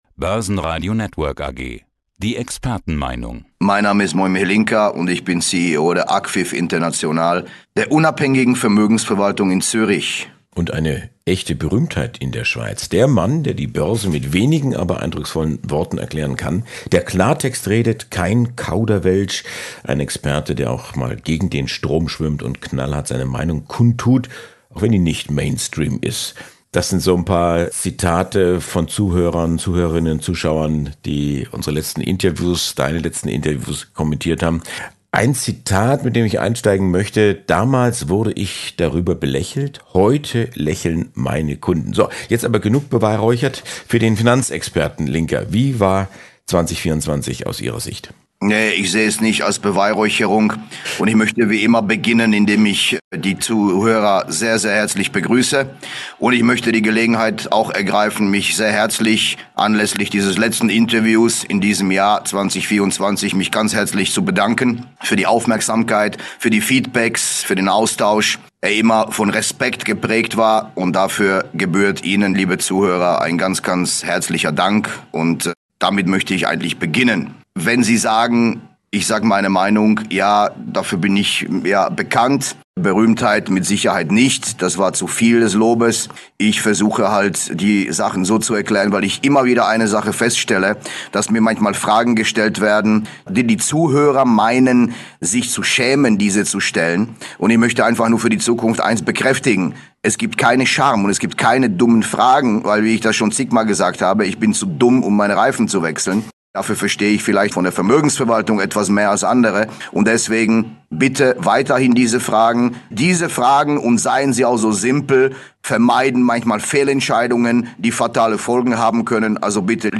(Eidg. dipl. Finanz- und Anlageexperte) im Gespräch